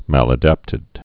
(mălə-dăptĭd)